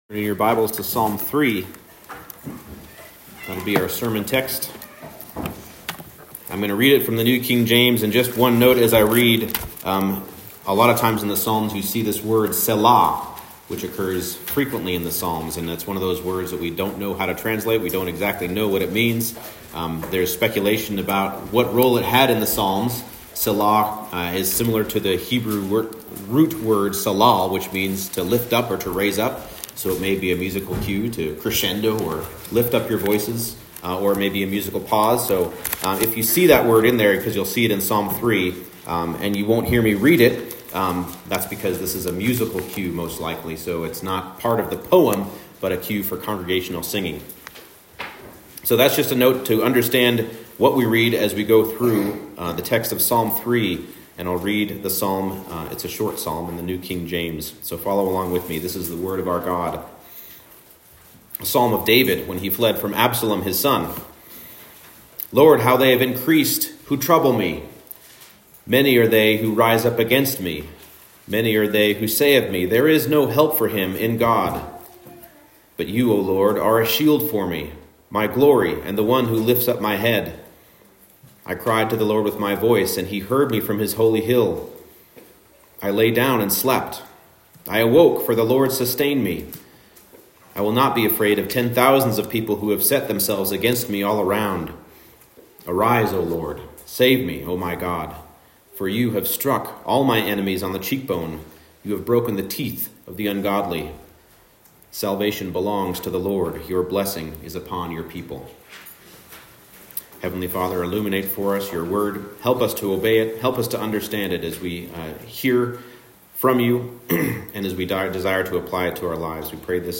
Psalm 3 Service Type: Morning Service Though men decry the believer's trust in God